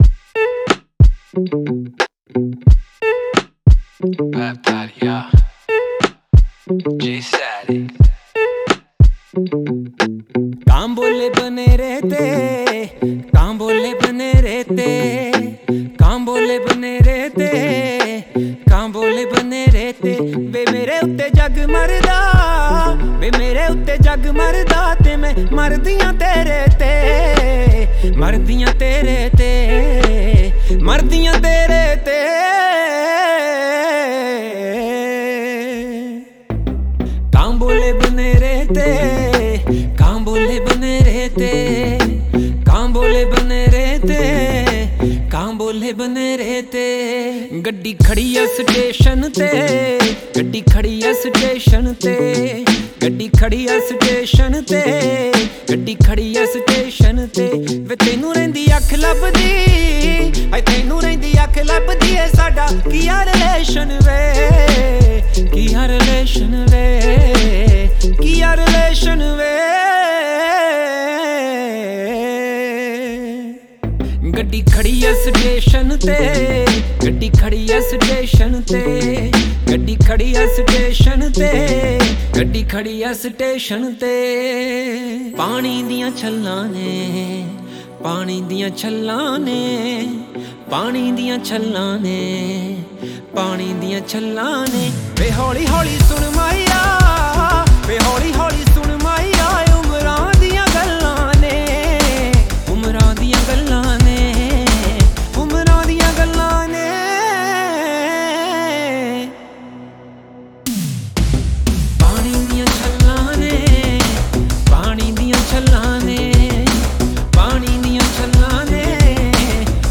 Punjabi Bhangra
Indian Pop